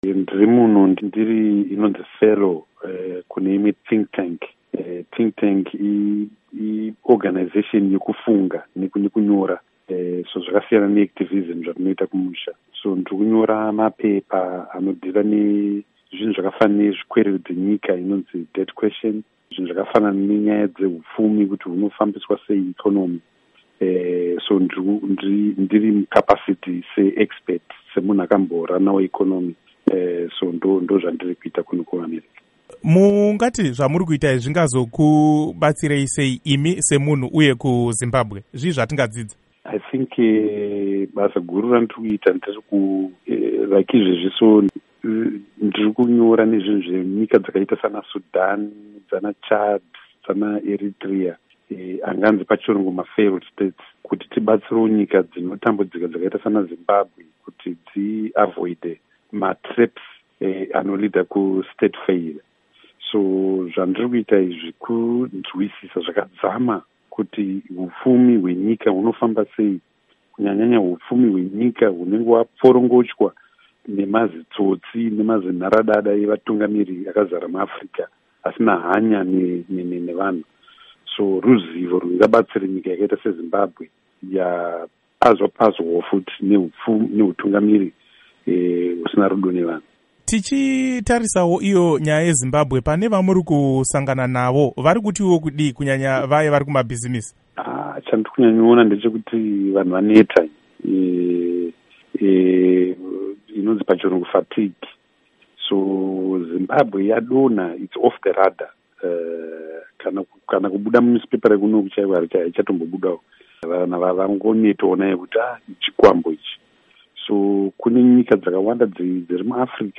Hurukuru naVaTendai Biti